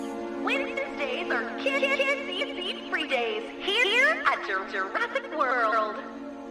A recording that plays over the park's P.A. system states that Wednesdays are kids-eat-free-days at Jurassic World, perhaps implying that this day that things fell apart in the park is a Wednesday.